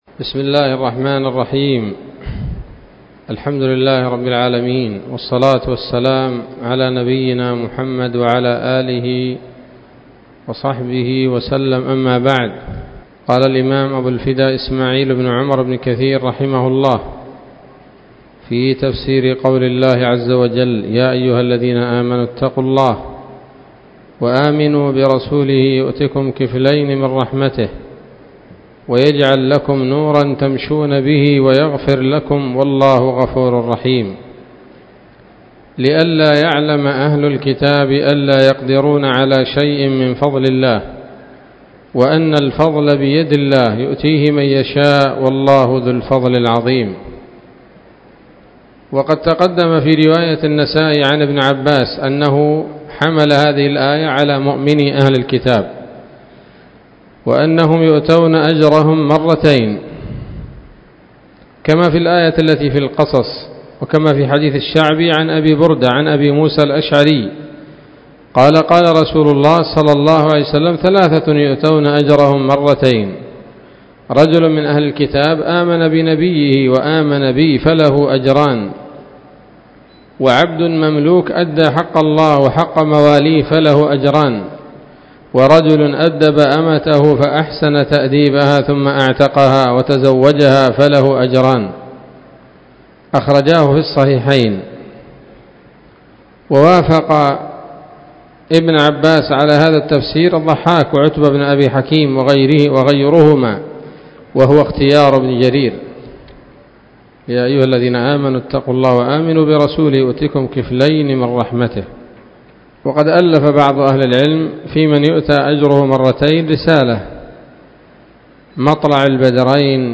الدرس الثاني عشر وهو الأخير من سورة الحديد من تفسير ابن كثير رحمه الله تعالى